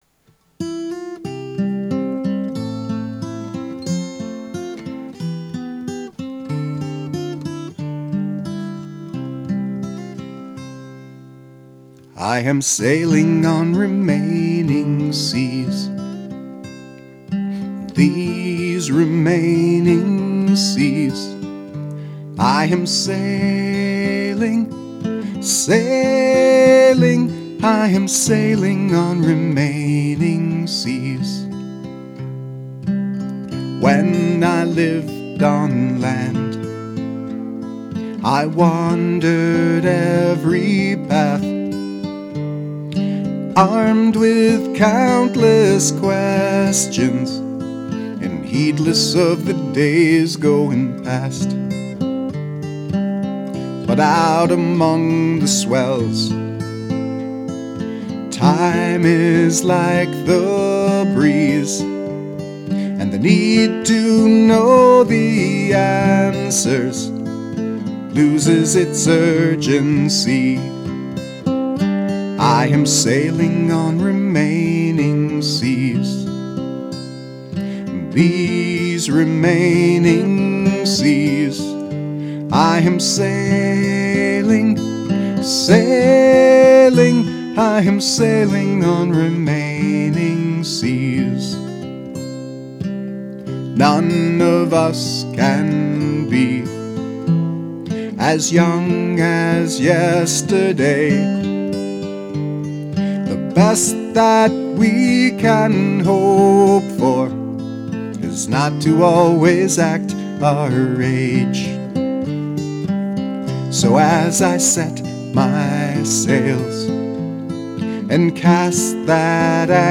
That was recorded in my “home studio” in March, 2005.